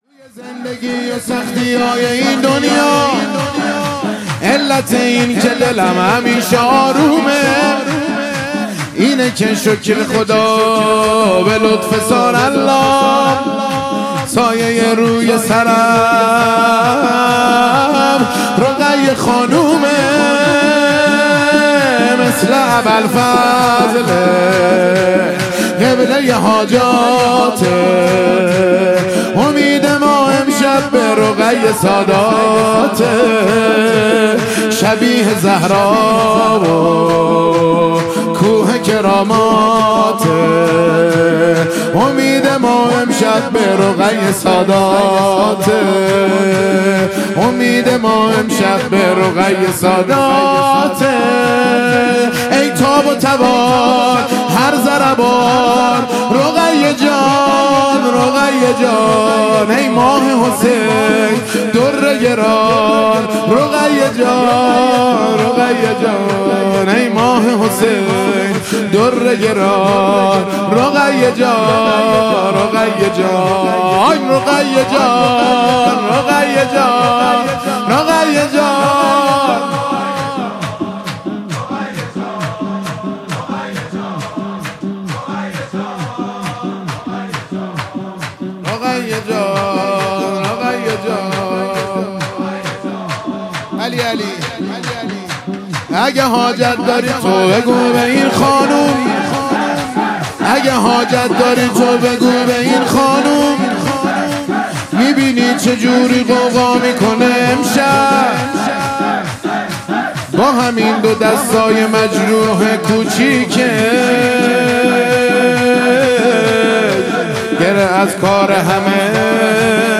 مراسم عزاداری شب سوم محرم الحرام - ۱۸ تیـــر ۱۴۰۳
شور
سایه روی سرم - مراسم عزاداری شب سوم محرم الحرام..mp3